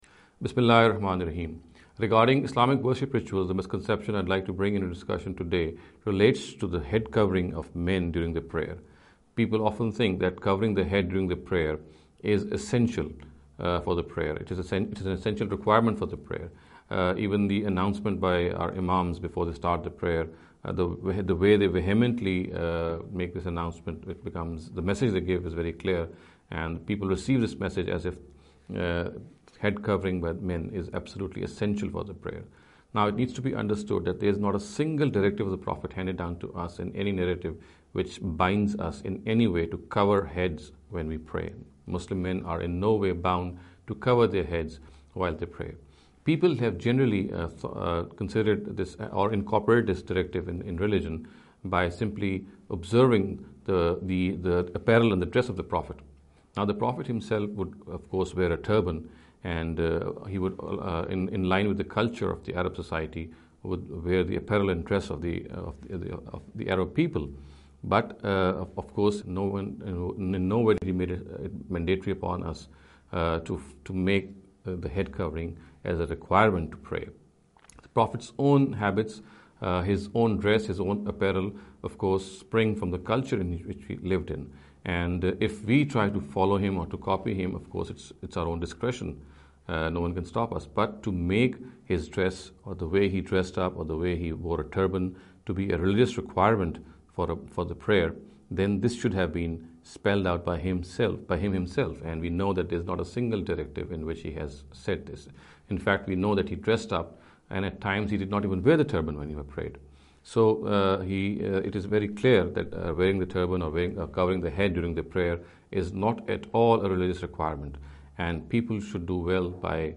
In the series of short talks “Islamic Worship Rituals